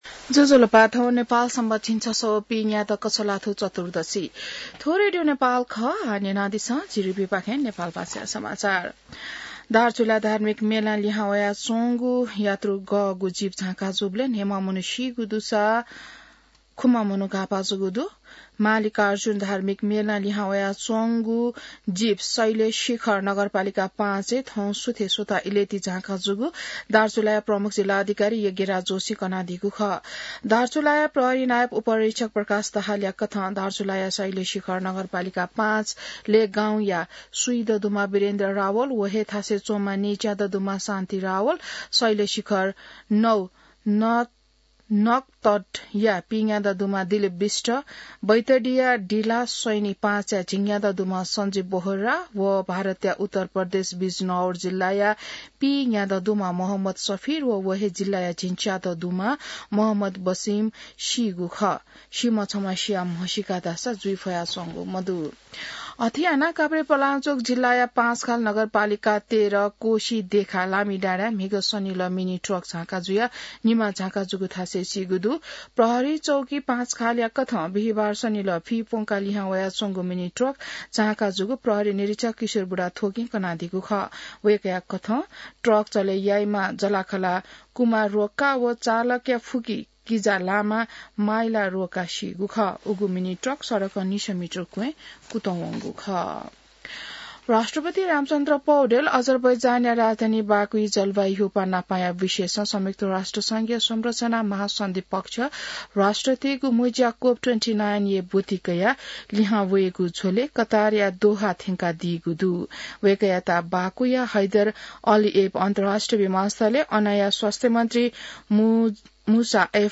नेपाल भाषामा समाचार : १ मंसिर , २०८१